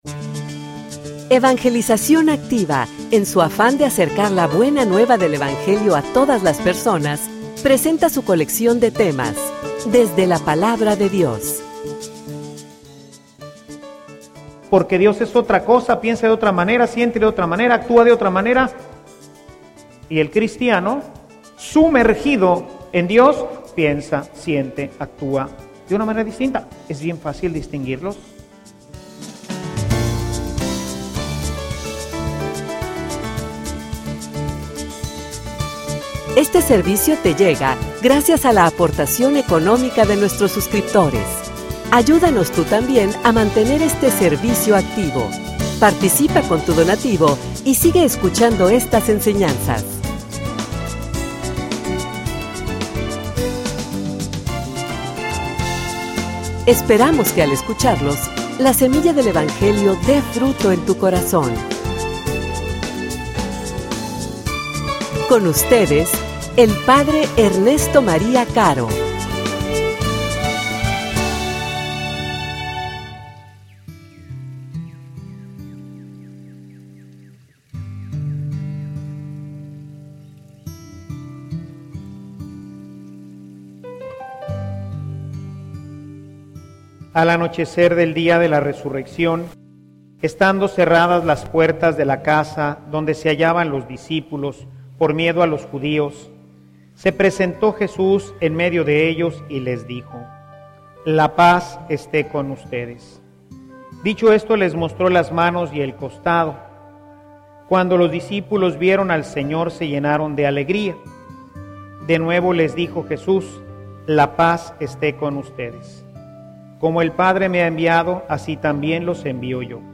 homilia_Llenos_del_Espiritu_de_santidad.mp3